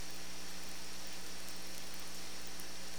single Zc Click (ca 108 kB)